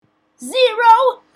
funny voices